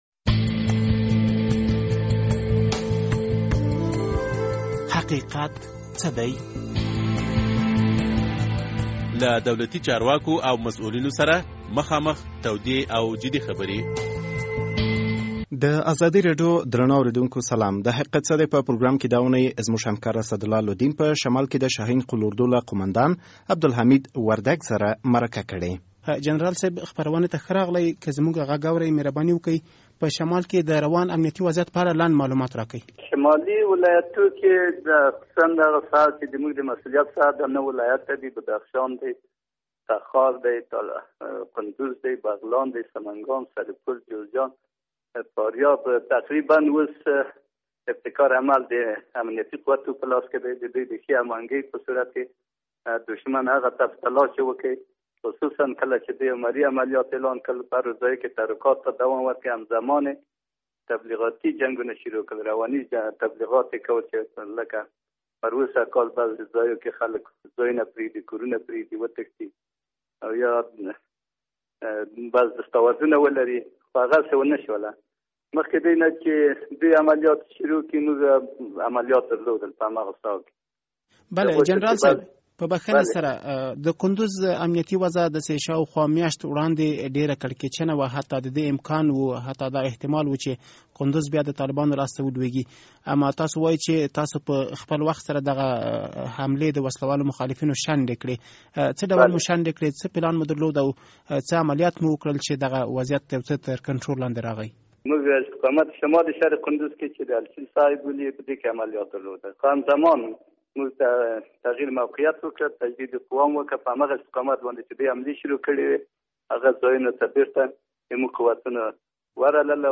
ښاغلي وردک د ازادي راډيو د حقیقت څه دی له پروګرام سره مرکه کې وویل چې په دغو ولایتونو کې د وسله والو مخالفینو تر عملیاتو مخکې افغان ځواکونو پراخ عملیات پیل کړي و.